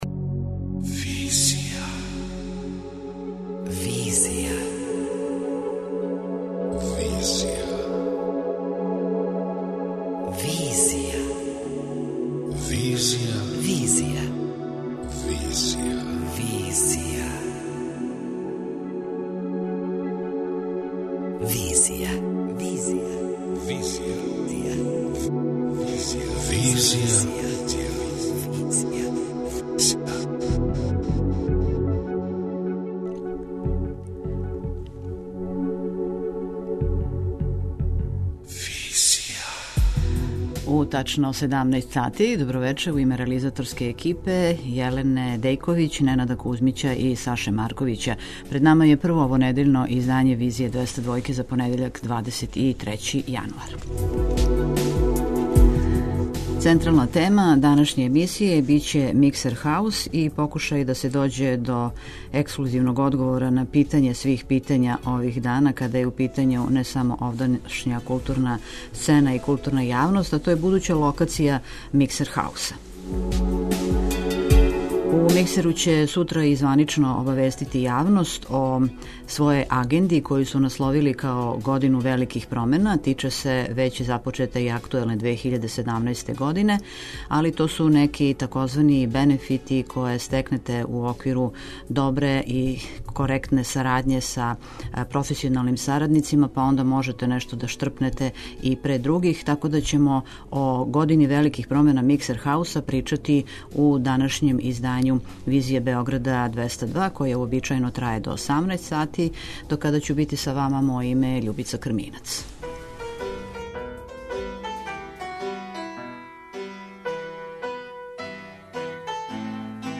преузми : 27.34 MB Визија Autor: Београд 202 Социо-културолошки магазин, који прати савремене друштвене феномене.